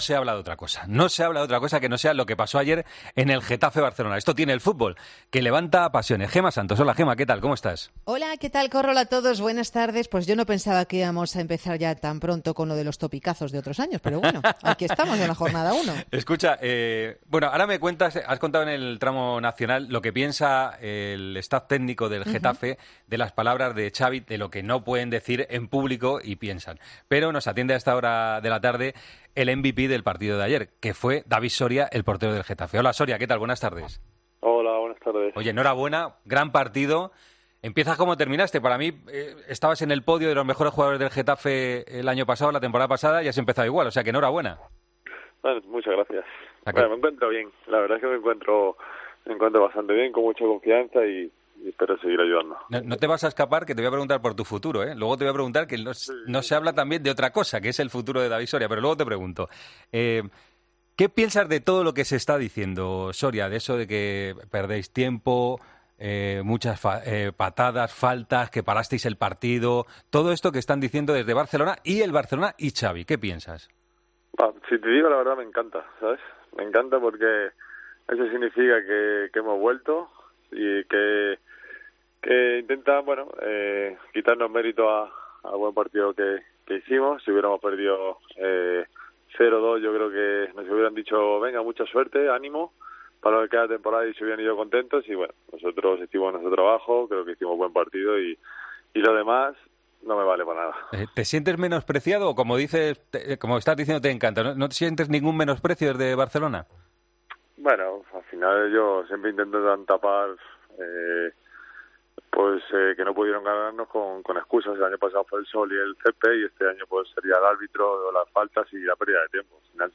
David Soria habló en Deportes COPE